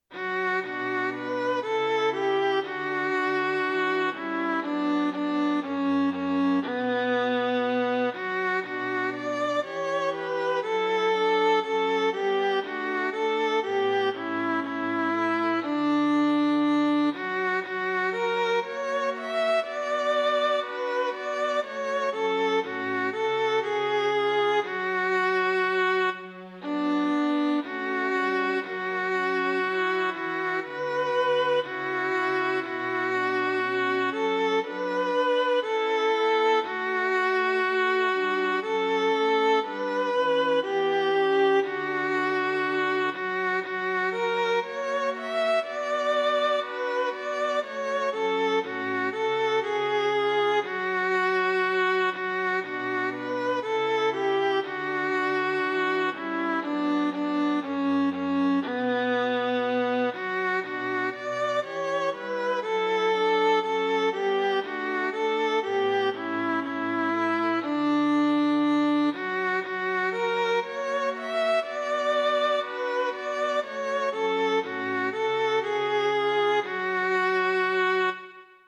Klik på linket 'Alt øve', 'Tenor øve' etc. for at høre korsatsen med fremhævet understemme.
SAB korsats bygget over egen melodi 2008
Sopran øve